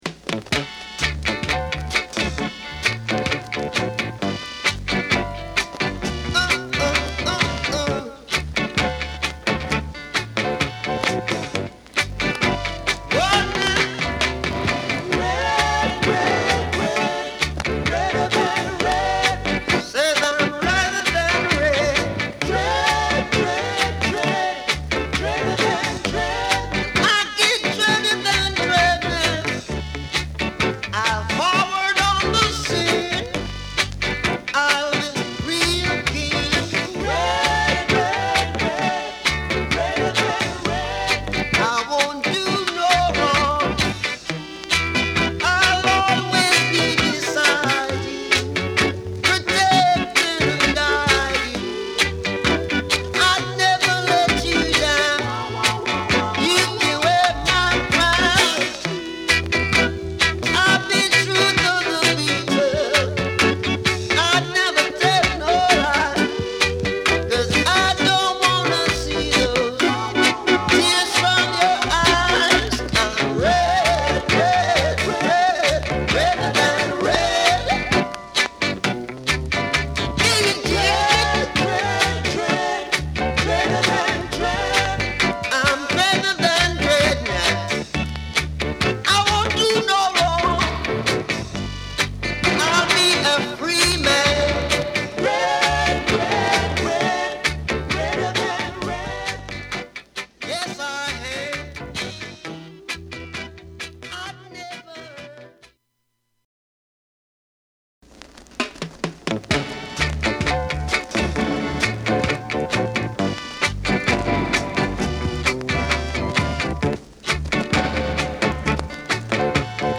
Genre: Reggae
ルーツレゲエへの移行期にあたる録音骨太なリズムトラックが特徴的だ。